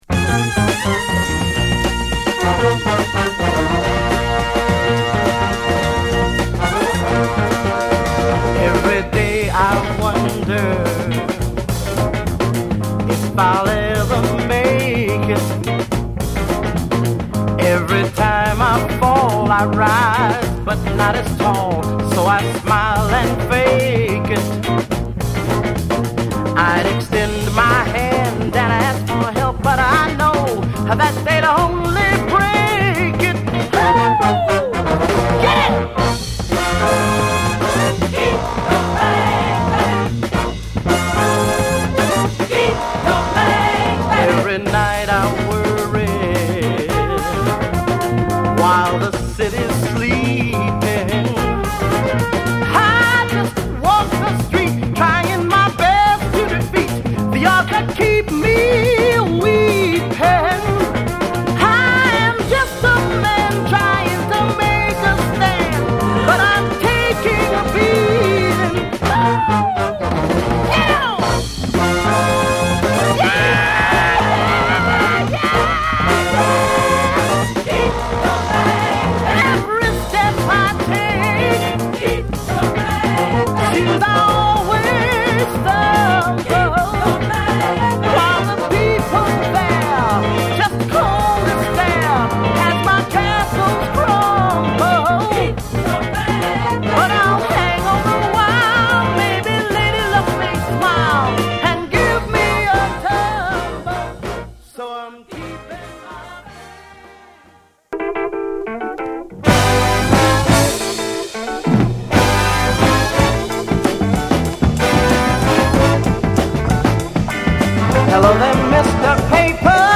ロック〜レアグルーヴ名作として知られる本作。